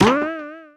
Trampoline sound effect from Super Mario 3D World.
SM3DW_Trampoline.oga